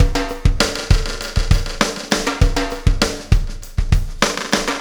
Pulsar Beat 05.wav